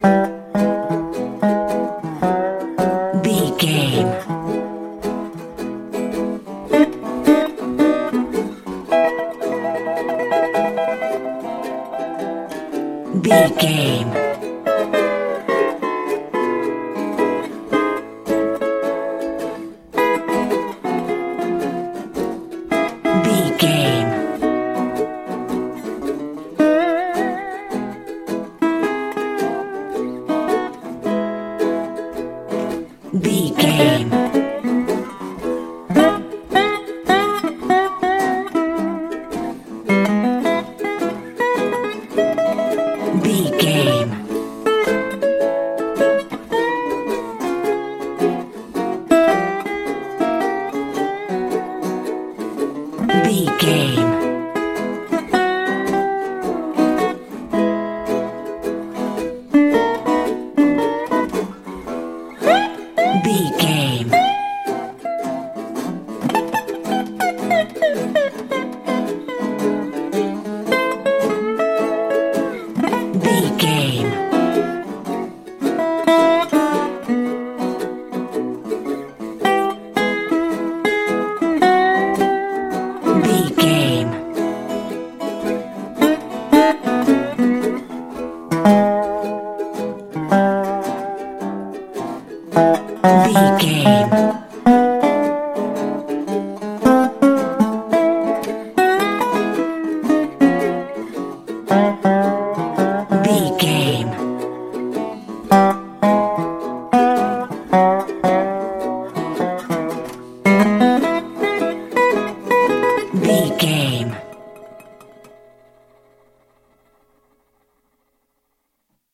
Ionian/Major
acoustic guitar
electric guitar
ukulele
slack key guitar